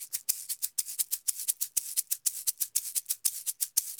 Eggs_ ST 120_1.wav